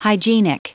Prononciation du mot hygienic en anglais (fichier audio)
Prononciation du mot : hygienic